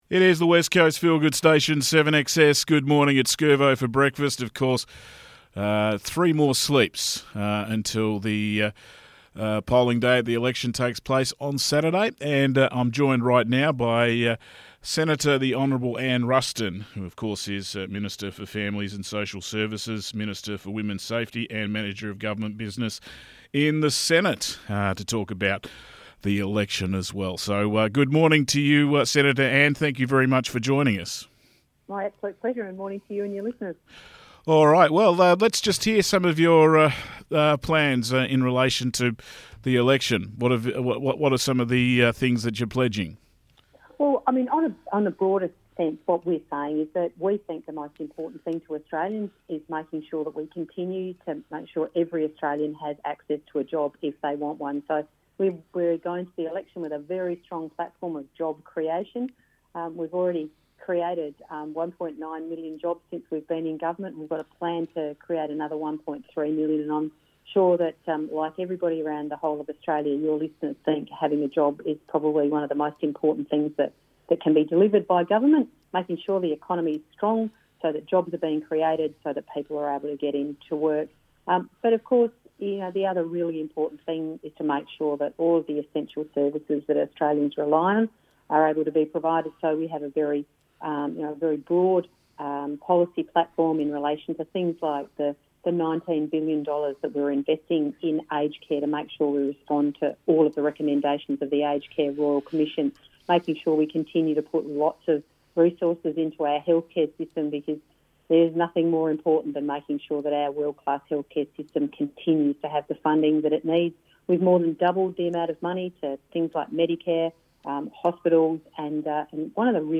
Interview with Senator Anne Ruston